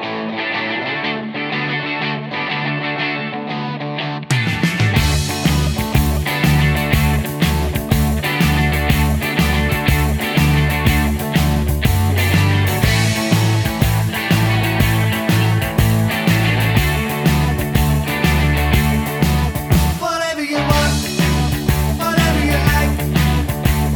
Minus Lead Guitar Rock 4:09 Buy £1.50